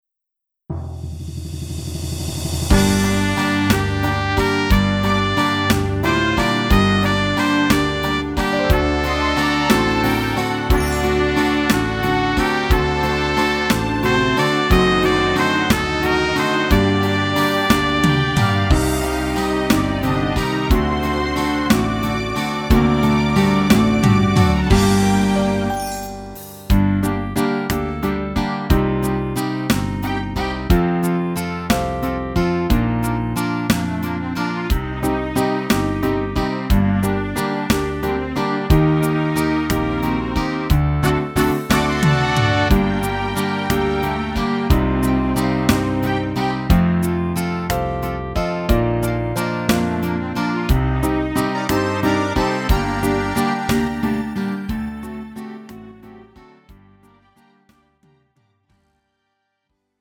음정 원키 3:31
장르 가요 구분 Lite MR
Lite MR은 저렴한 가격에 간단한 연습이나 취미용으로 활용할 수 있는 가벼운 반주입니다.